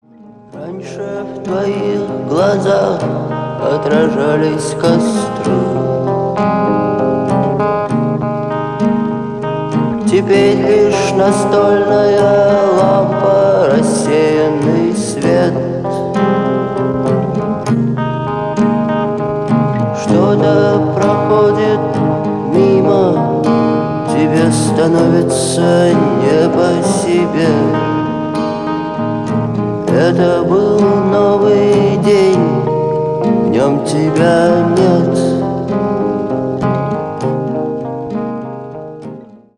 • Качество: 224, Stereo
грустные
спокойные
медленные
ретро
акустическая гитара